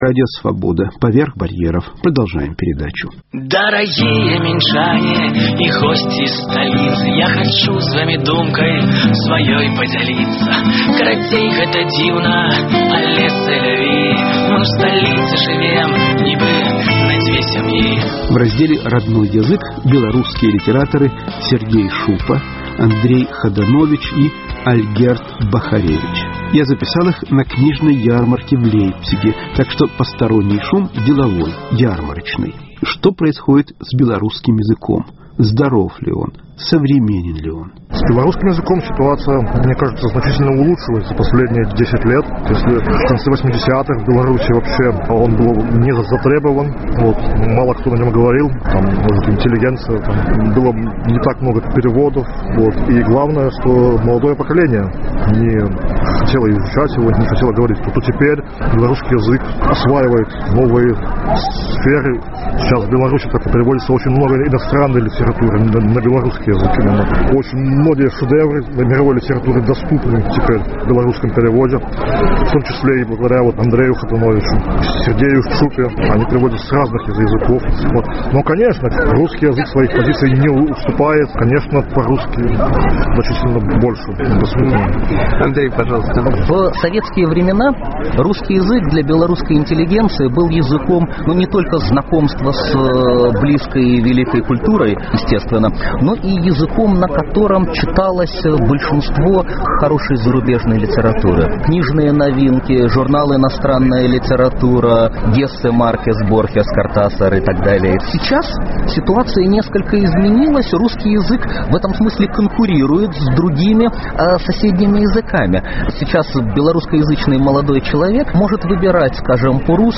"Родной Язык" (беседа с белорусскими литераторами).